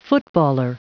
Prononciation du mot footballer en anglais (fichier audio)
Prononciation du mot : footballer